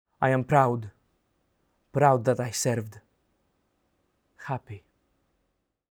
Audio dramatisation based on the Memoirs of a Prisoner.